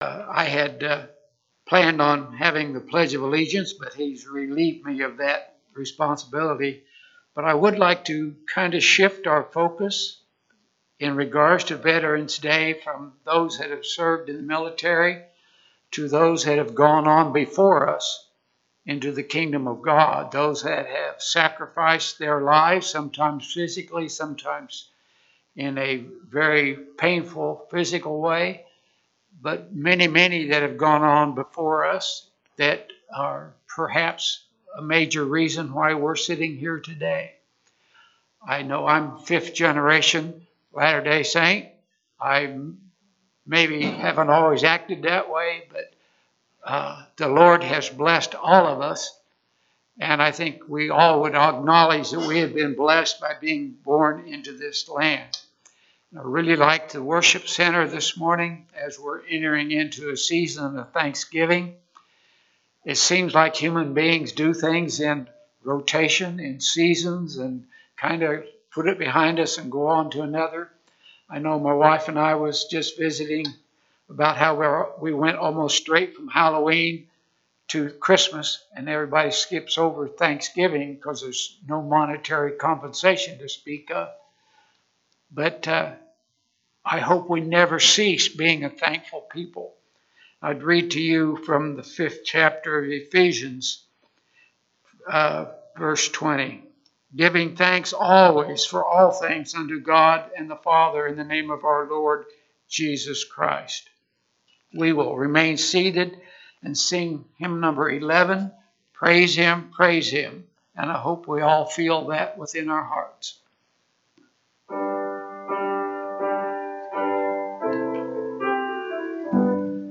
Lexington Restoration Branch - November 10, 2024 Service - Playeur